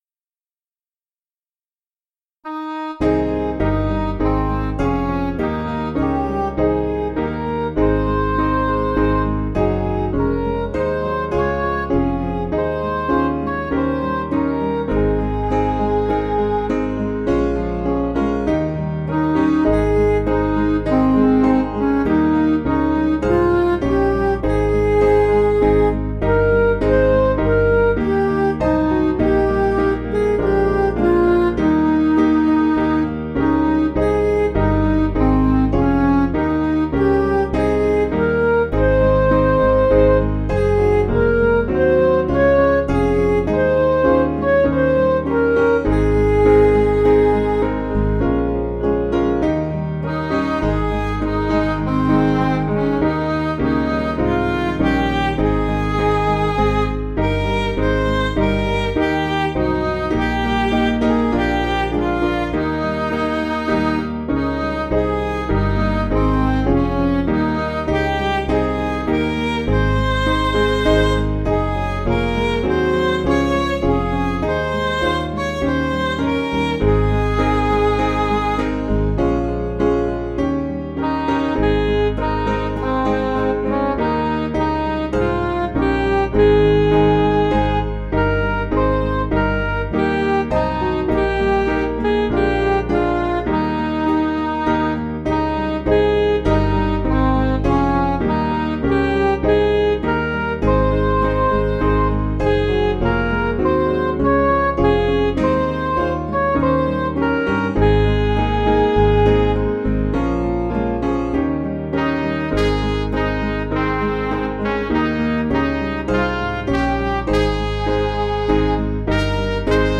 Piano & Instrumental
(CM)   4/Ab